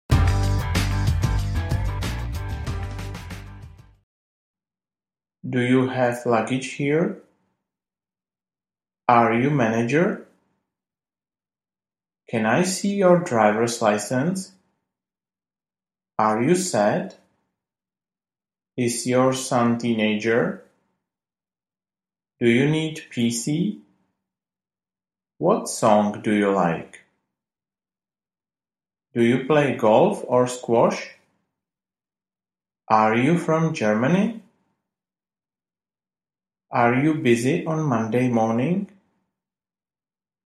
Audiokniha
Otázky se postupně zrychlují.